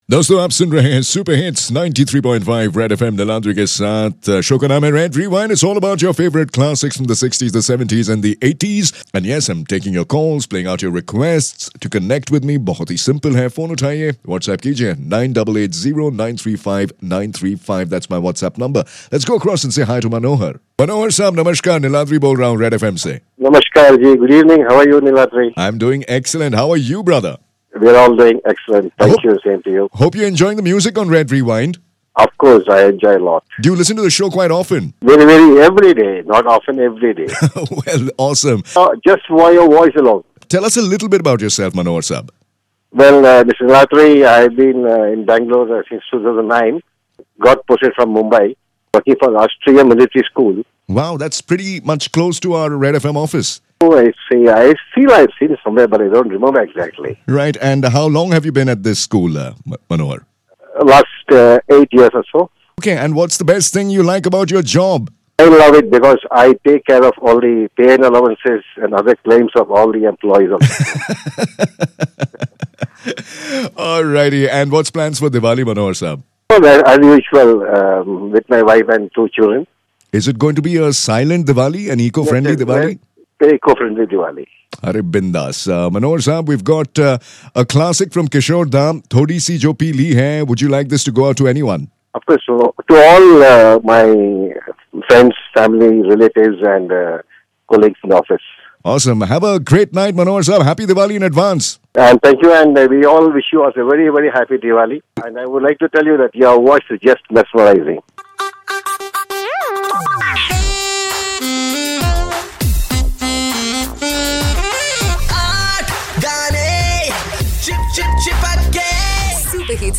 Some voices are just mesmerizing... Like this one...